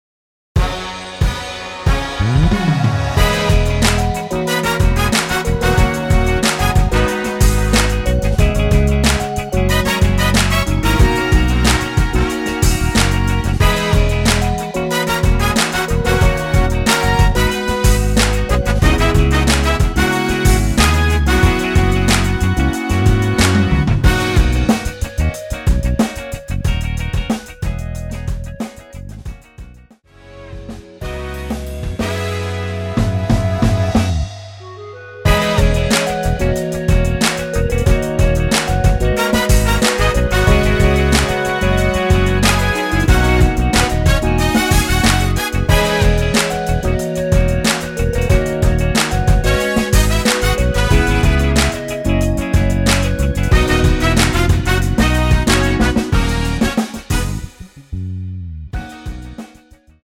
원키에서(-1)내린 멜로디 포함된 MR입니다.(미리듣기 확인)
◈ 곡명 옆 (-1)은 반음 내림, (+1)은 반음 올림 입니다.
멜로디 MR이라고 합니다.
앞부분30초, 뒷부분30초씩 편집해서 올려 드리고 있습니다.
중간에 음이 끈어지고 다시 나오는 이유는